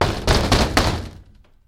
卧室门
Tag: 开口 关闭